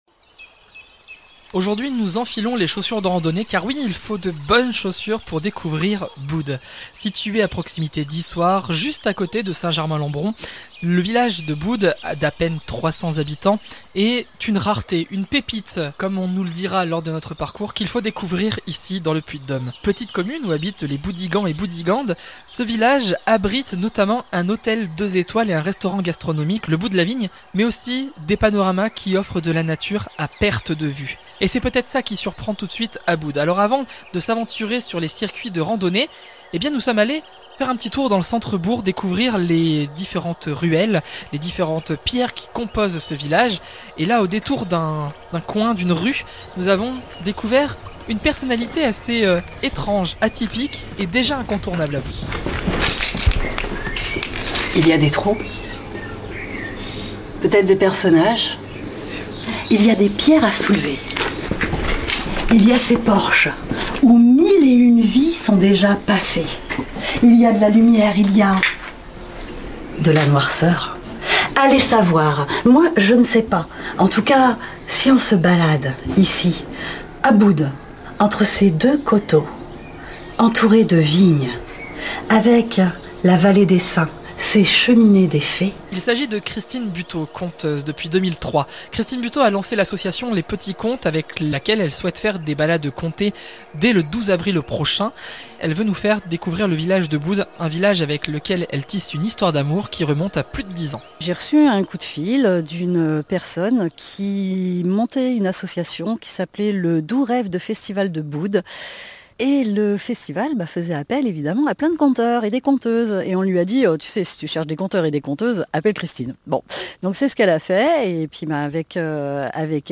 A travers ces trois regards, ces trois femmes qui sont de celles qui font vivre Boudes, nous allons en apprendre un peu plus sur ce village décidément pas comme les autres...